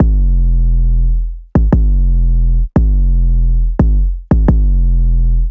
• phonk kick loop saturated degraded - 808 E 87.wav
Hard punchy kick sample for Memphis Phonk/ Hip Hop and Trap like sound.